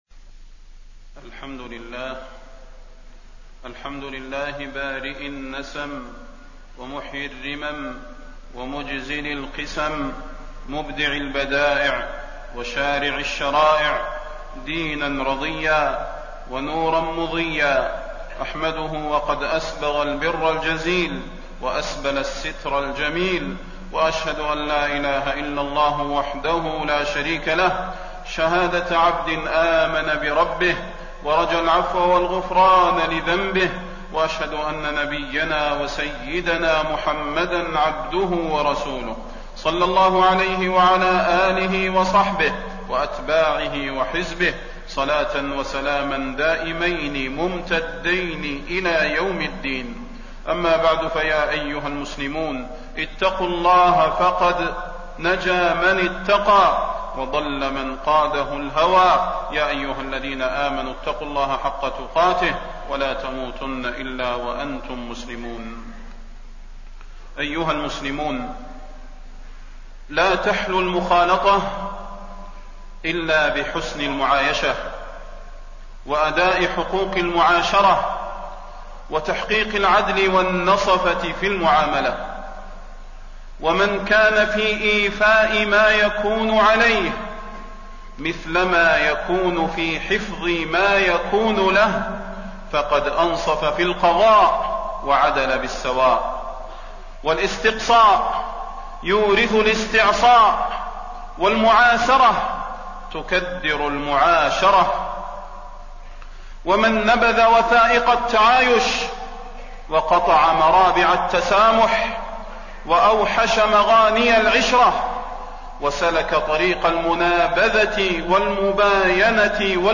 تاريخ النشر ٢١ محرم ١٤٣٣ هـ المكان: المسجد النبوي الشيخ: فضيلة الشيخ د. صلاح بن محمد البدير فضيلة الشيخ د. صلاح بن محمد البدير حسن العشرة بين المسلمين